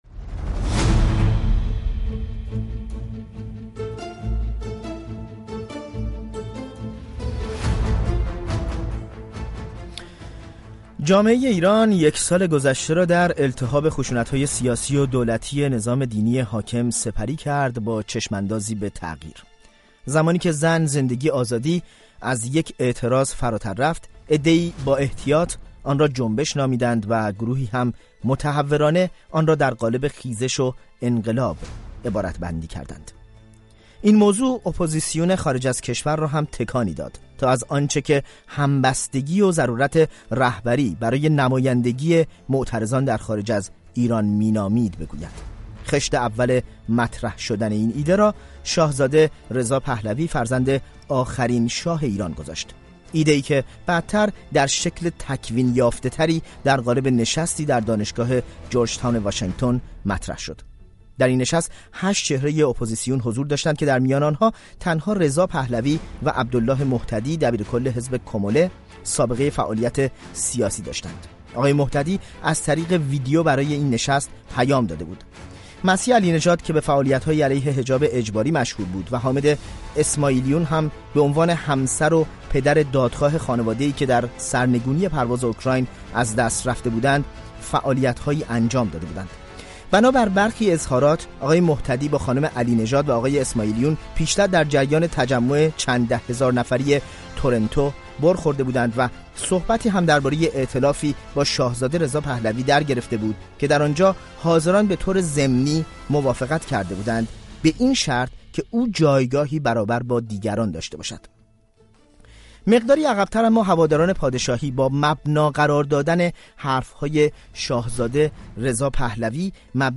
میزگرد رادیویی: درسی از پیوست و گسست اپوزیسیون در زمانه اعتراضات